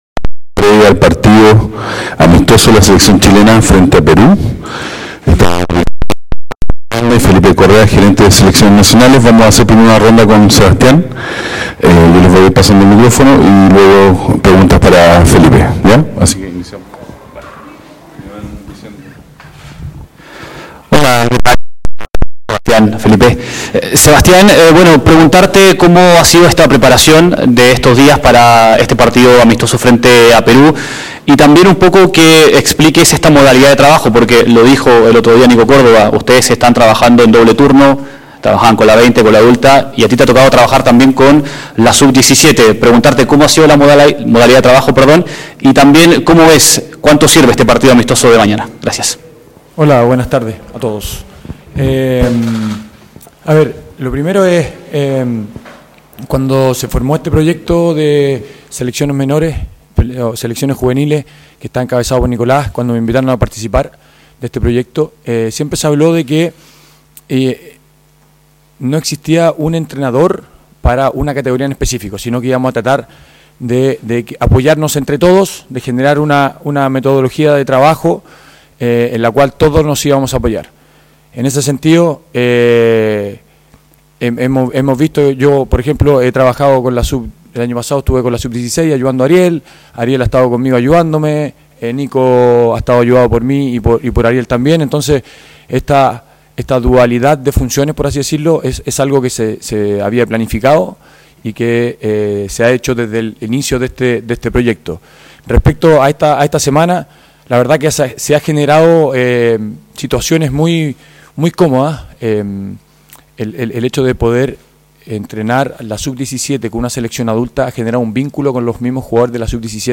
Sebastián Miranda en conferencia de prensa